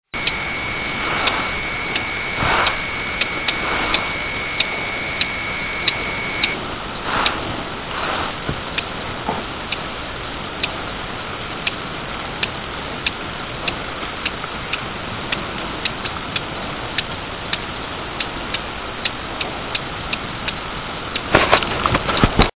Brun Løvsanger  Phylloscopus fuscatus  DK listen
Lokalitet:Korshage
lydoptagelse af kald